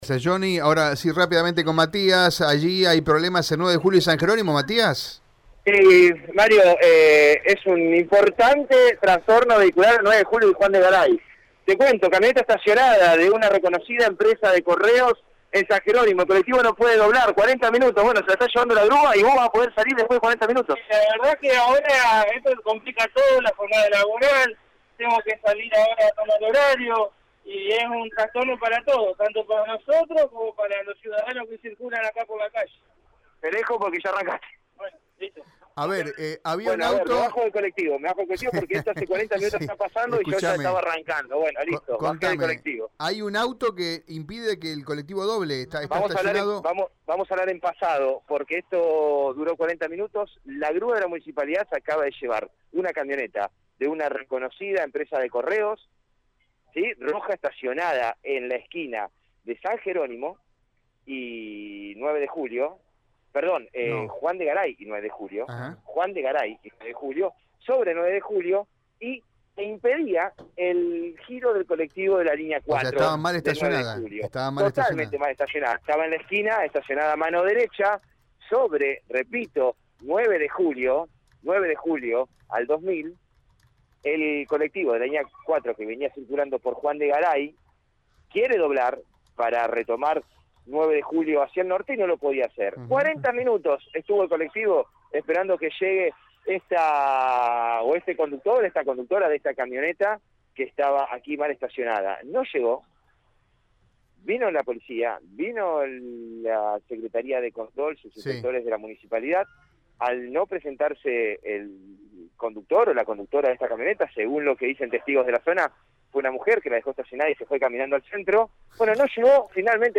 «Esto complica a toda la jornada laboral. Es un trastorno para nosotros como para los ciudadanos», expresó el colectivero en diálogo con el móvil de Radio EME.